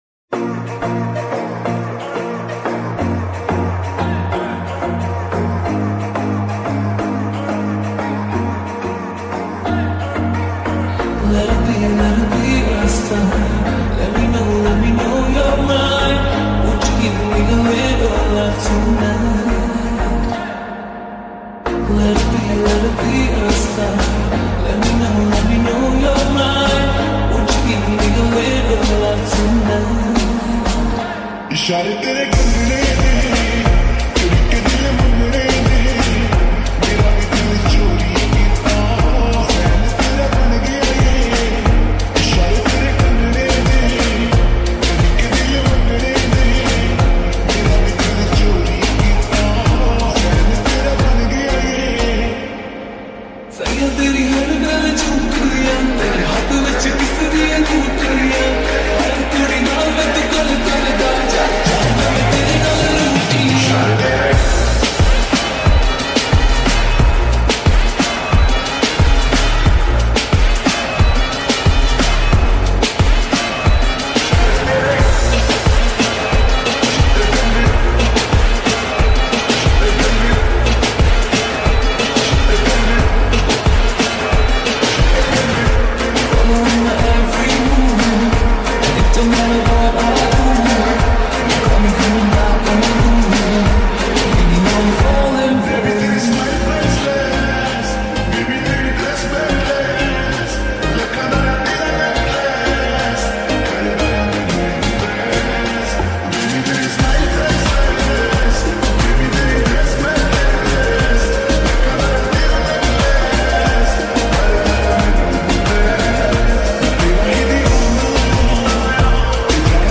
slowed+REVERV